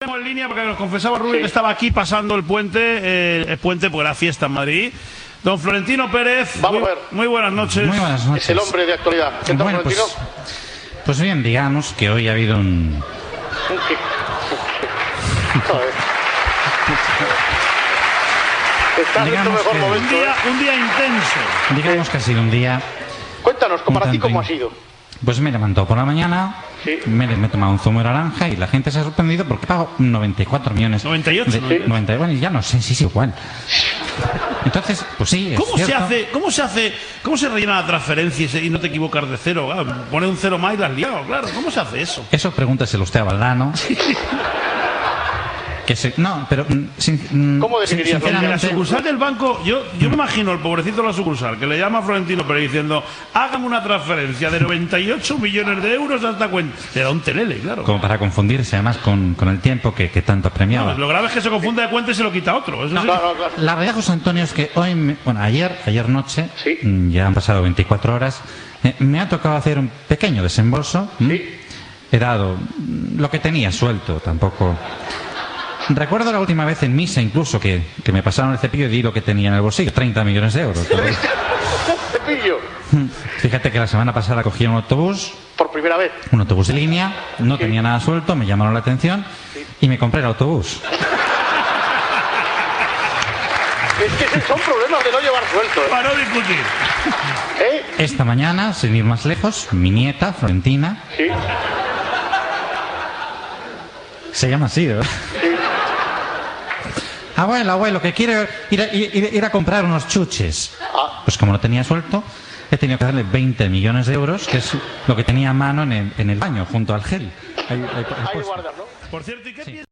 Secció de El Grupo Risa amb la imitació del president del Real Madrid Florentino Pérez després que aquest fitxés al jugador Cristiano Ronaldo per al Real Madrid (per uns 93 milions d'euros). La imitació del periodista José María García ho escolta per telèfon
Esportiu